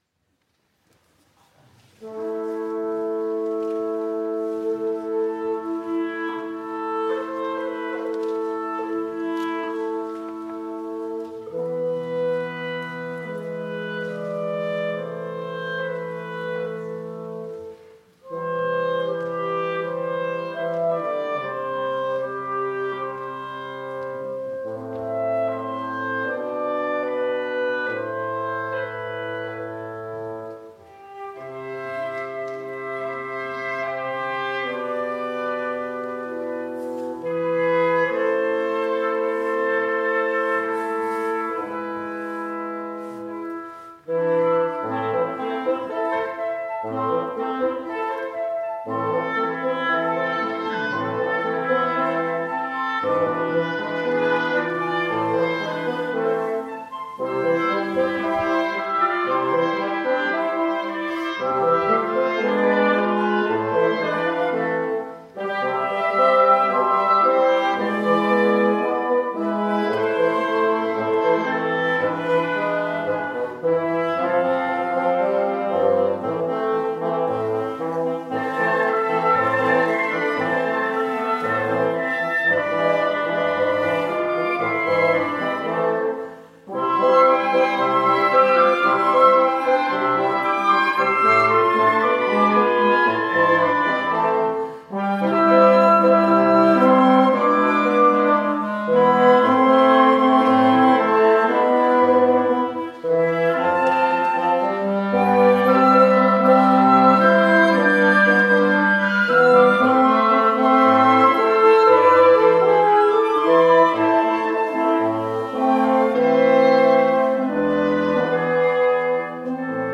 Beitrags-Navigation Veröffentlicht in Impressionen zur Ausstellungseröffnung „Wehrhafte Demokratie“ am 4.11.2024 im Landtag Niedersachsen